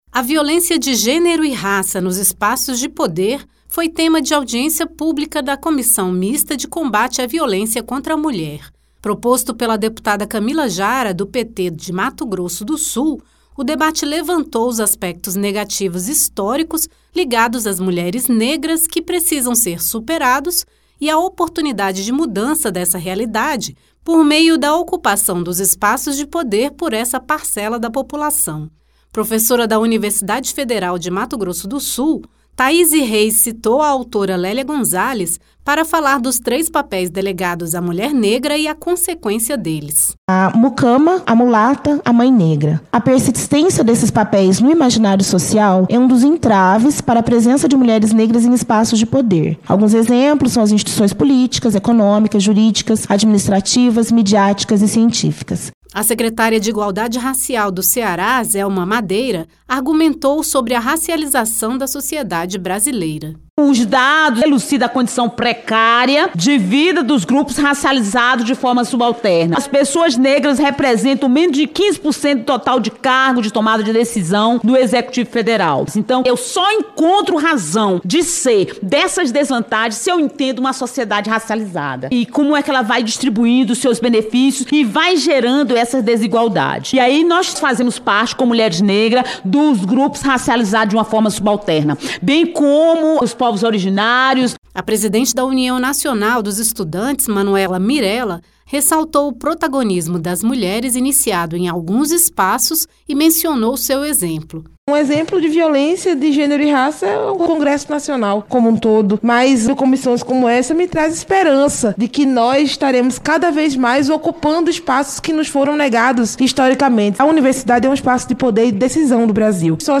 Uma mudança lenta no protagonismo dessa parcela da população já é percebida, mas os desafios persistem e foram enfatizados durante a audiência pública.